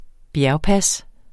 Udtale [ ˈbjæɐ̯w- ]